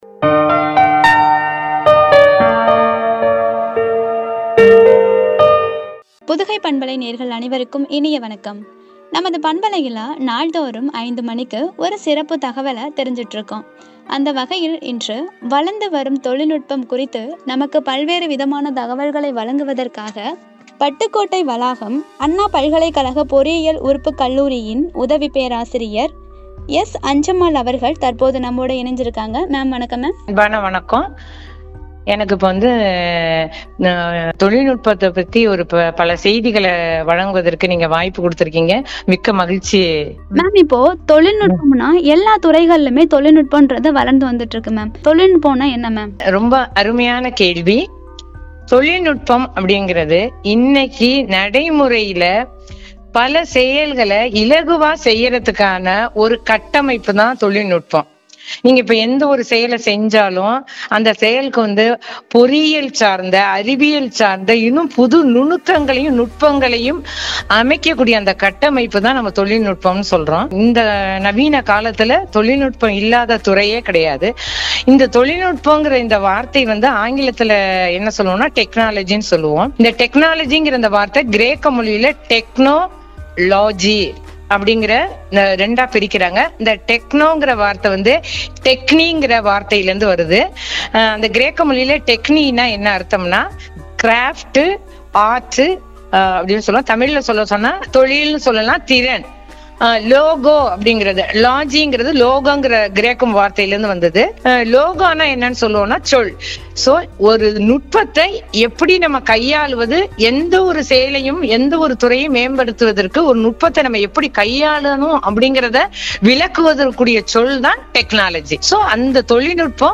என்ற தலைப்பில் வழங்கிய உரையாடல்.*********